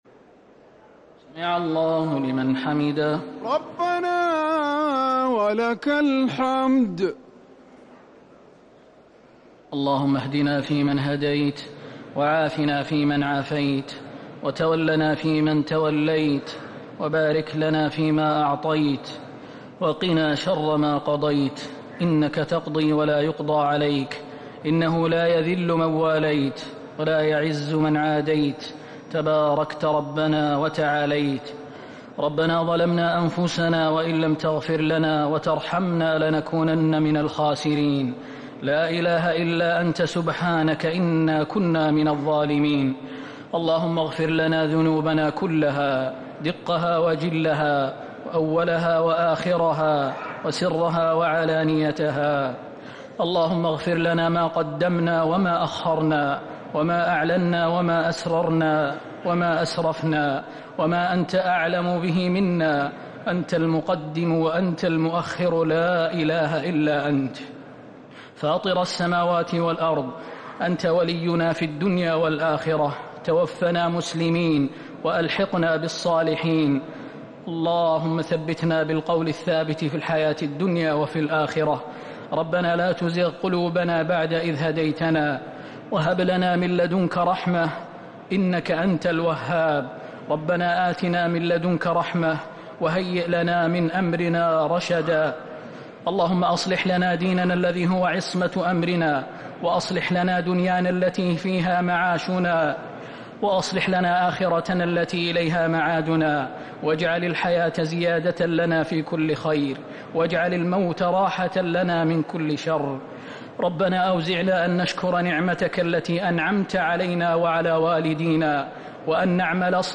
دعاء القنوت ليلة 3 رمضان 1447هـ | Dua 3rd night Ramadan 1447H > تراويح الحرم النبوي عام 1447 🕌 > التراويح - تلاوات الحرمين